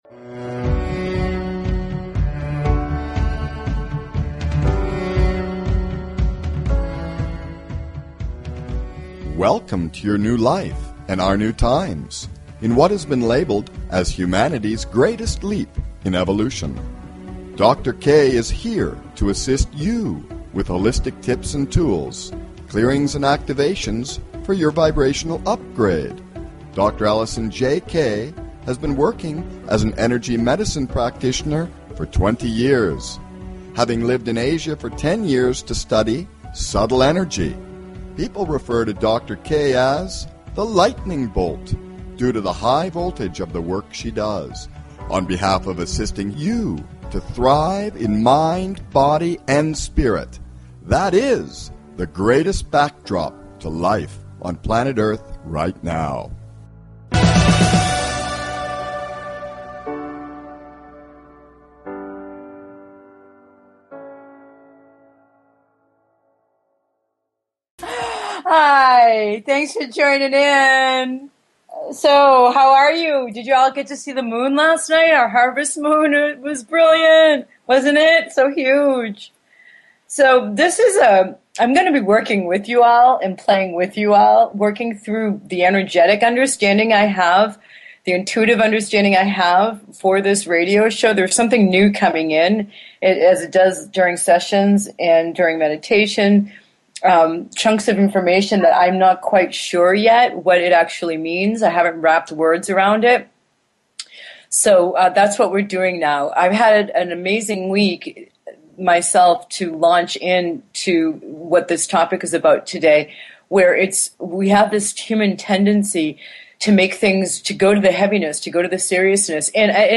Talk Show Episode, Audio Podcast, Vibrational UPgrade and Easing it UP: Allowing Ourselves to Drop Making Stuff Serious So We Can Play More - How To? on , show guests , about Easing It Up,Making Stuff Serious,Allowing Ourselves,We Can Play More, categorized as Health & Lifestyle,Kids & Family,Philosophy,Psychology,Self Help,Spiritual